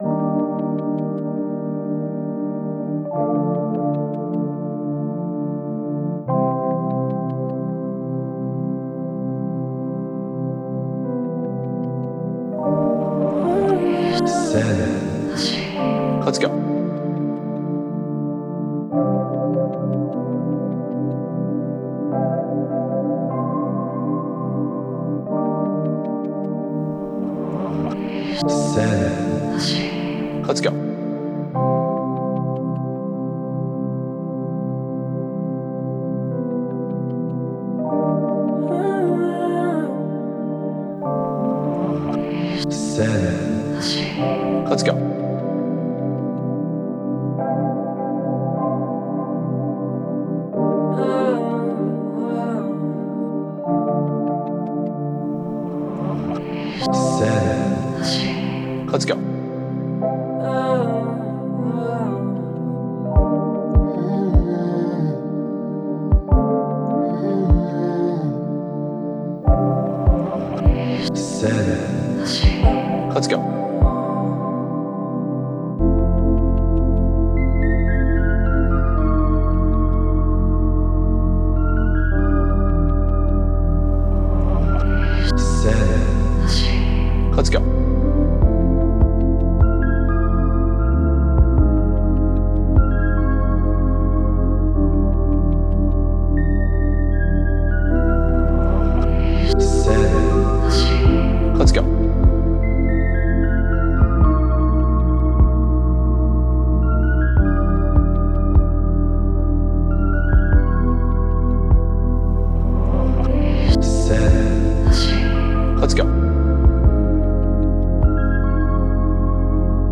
Smooth – R&B – Trapsoul – Type Beat
Key: C#m
76 BPM